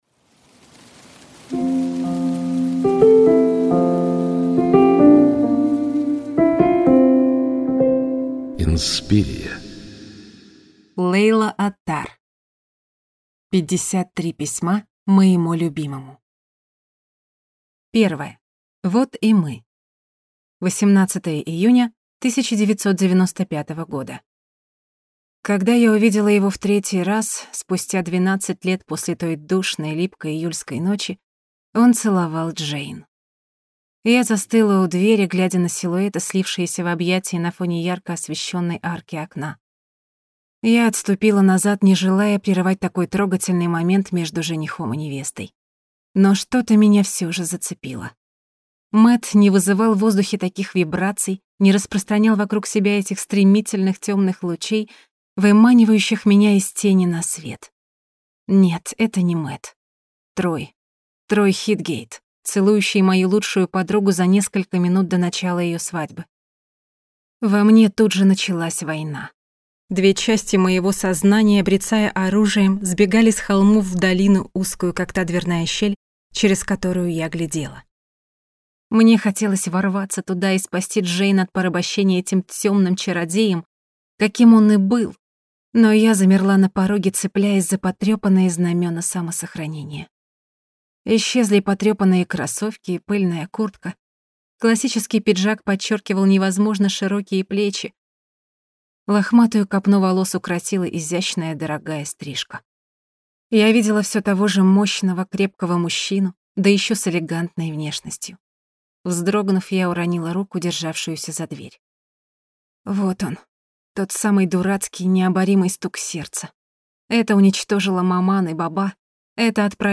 Аудиокнига Пятьдесят три письма моему любимому | Библиотека аудиокниг